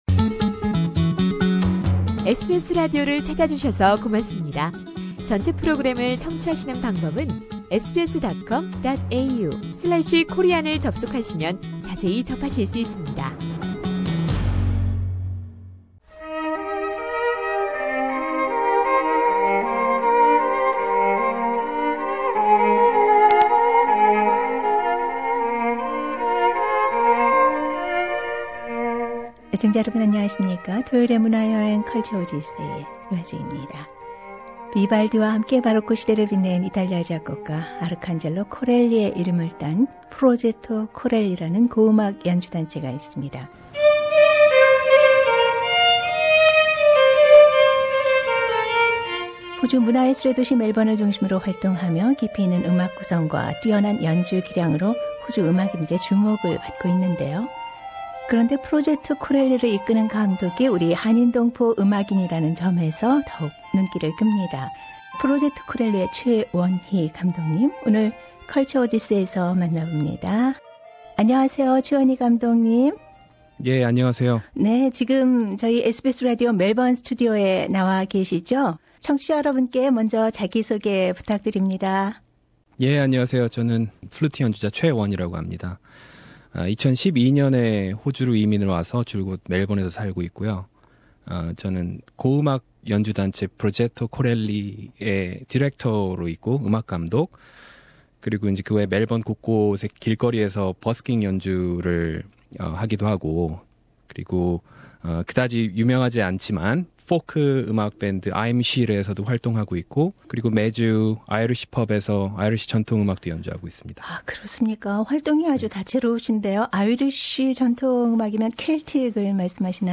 [인터뷰] 고음악(Early Music